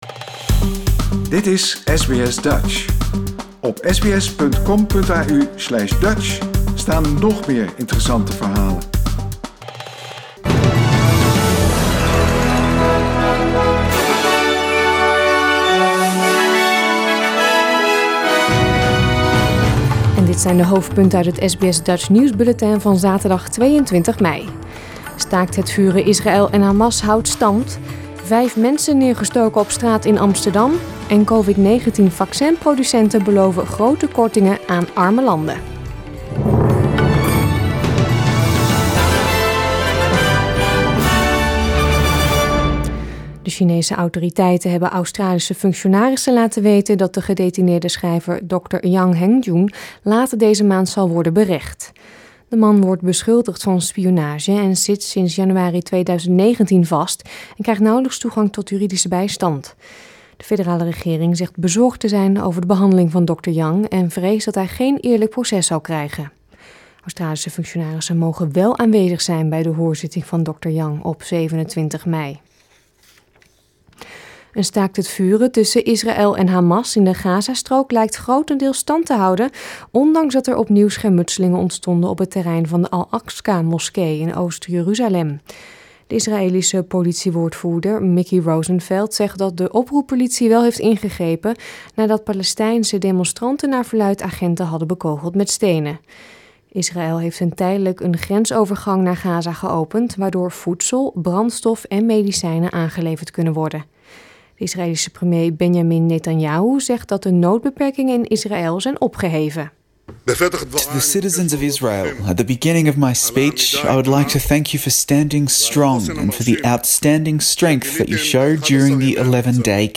Nederlands/Australisch SBS Dutch nieuwsbulletin van zaterdag 22 mei 2021